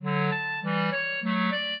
clarinet
minuet11-10.wav